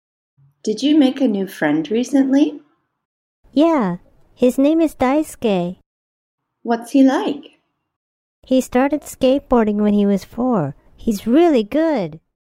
Lesson03_Step4_2_slow.mp3